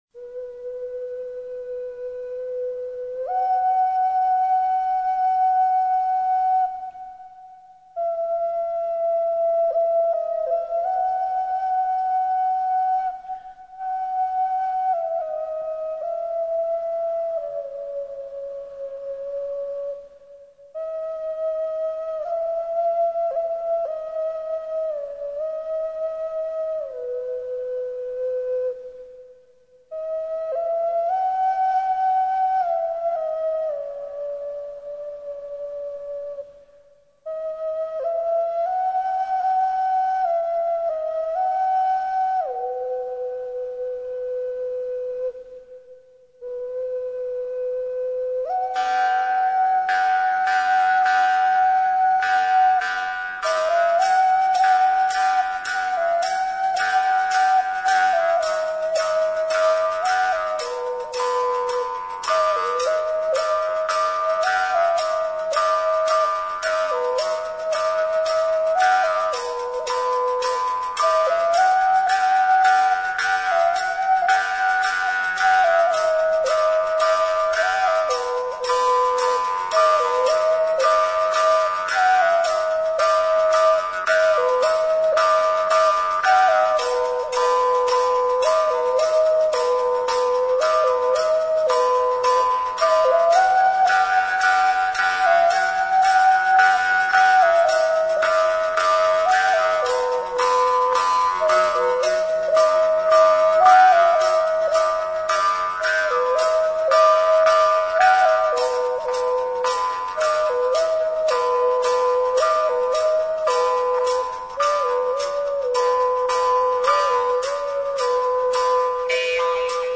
Саз-сырнай, Асатаяк, Шанкобыз